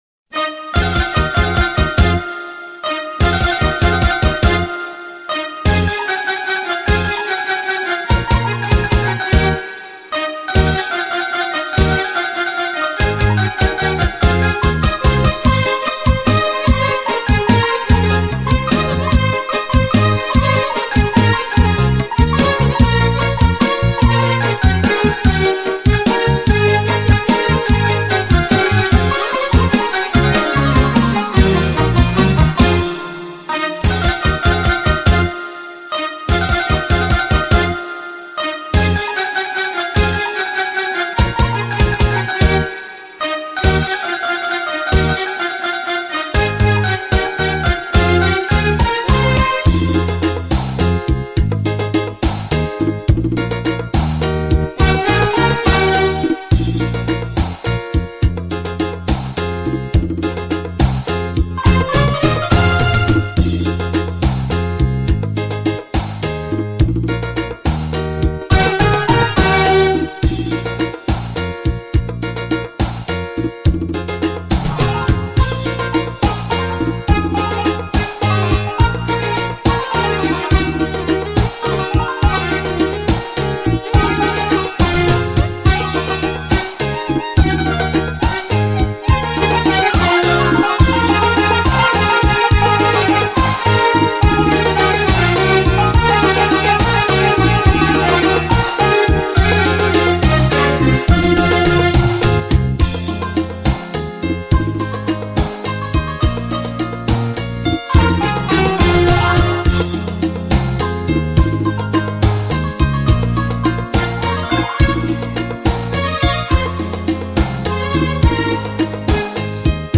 در دستگاه شور به اجرا درآمده است